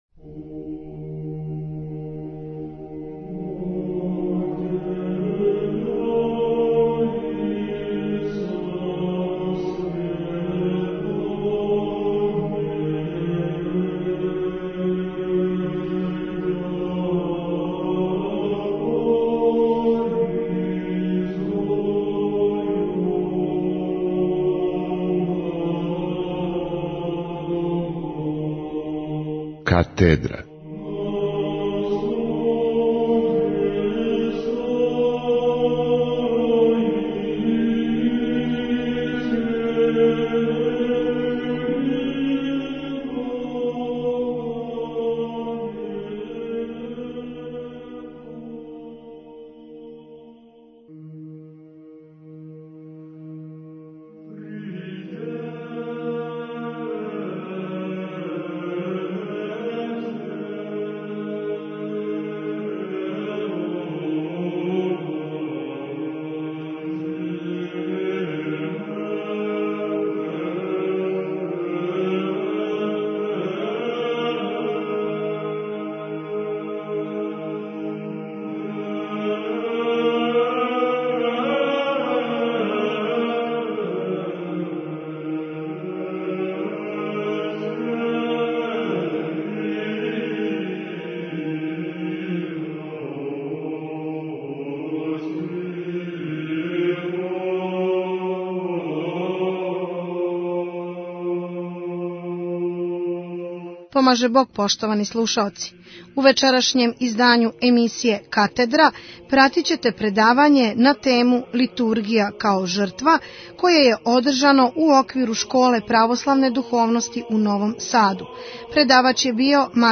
Литургија као жртва Tagged: Катедра 44:46 минута (7.69 МБ) У оквиру Школе православне духовности у Новом Саду одржано је у недељу, 26. децембра 2010. године предавање на тему Литургија као жртва.